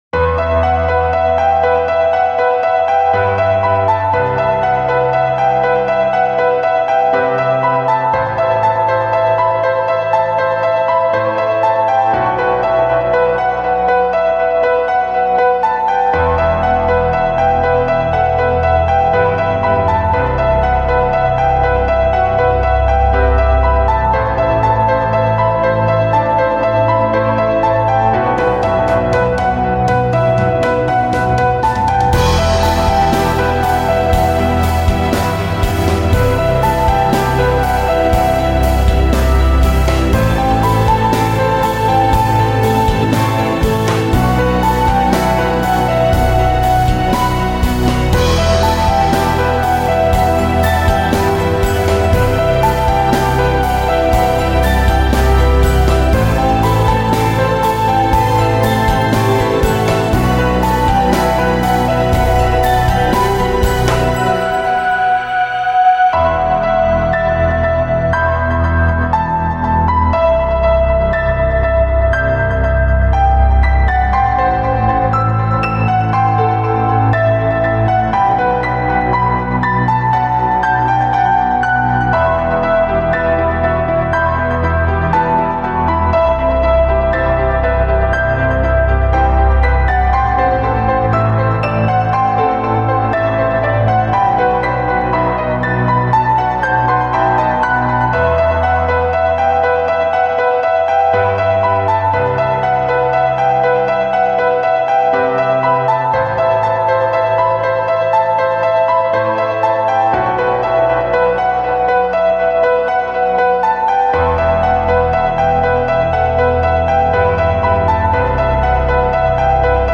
...гарно сприймається і відчувається на фоні музики... дуже чуттєво 12 16 give_rose